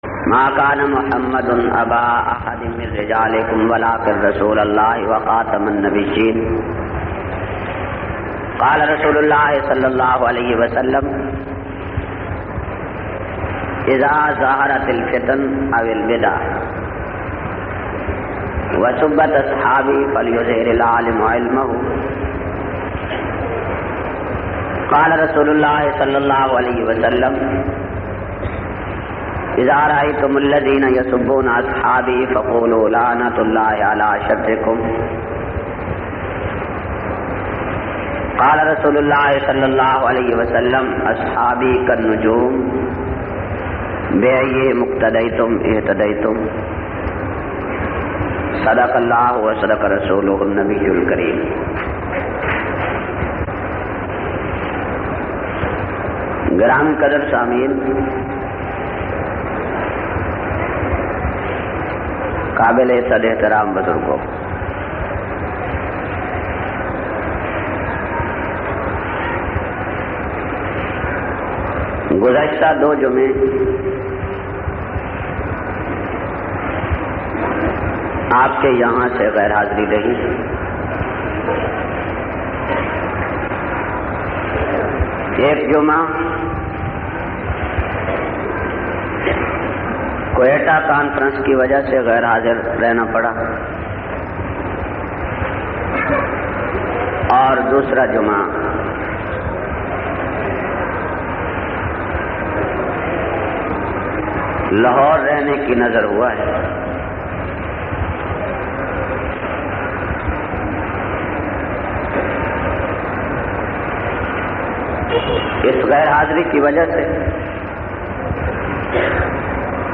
367- Shahadat e Hussain Ka Pasmanzer-Jumma,Jhang Sadar.mp3